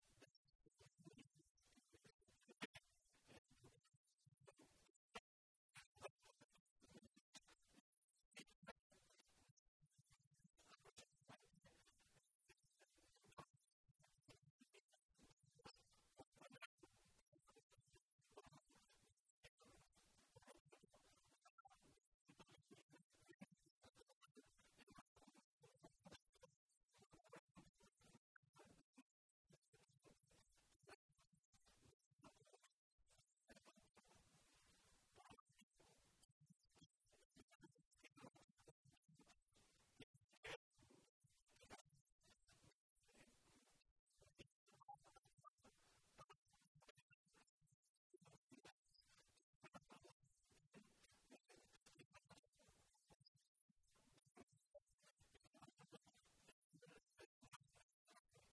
José Luis Martínez Guijarro, portavoz del Grupo Parlametario Socialista
Cortes de audio de la rueda de prensa